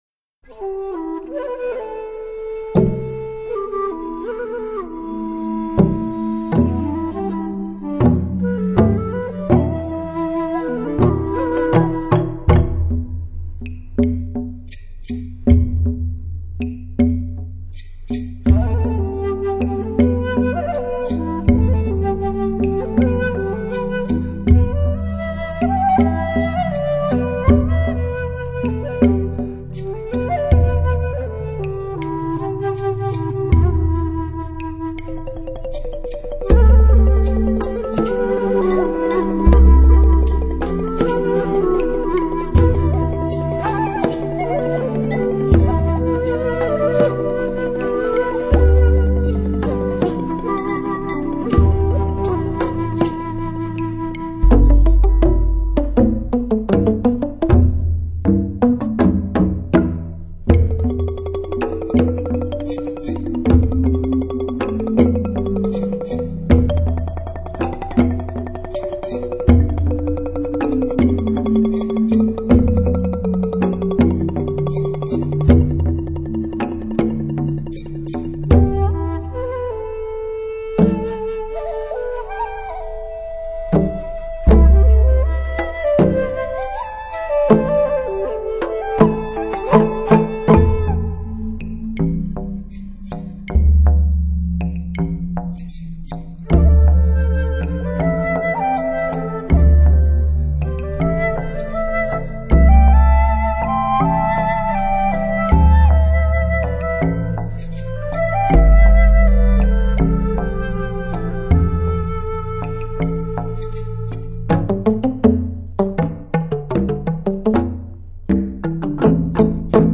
最纯粹—最原始—最真实的竹乐
失传了千百年的巨型竹相首次登场，超重低音——撼动心神叹为听止！
首次呈现竹之巨幅超重低音，洗翰动感 绝无仅有。